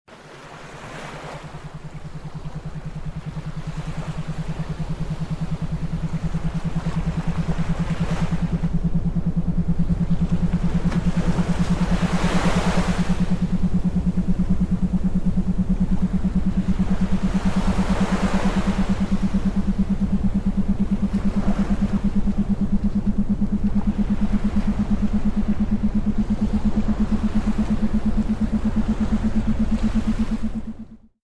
מוזיקה עם תדרים מרפאים
האזינו לדוגמה מתוך תדר תטא בכוונון 432 להרפייה וריפוי: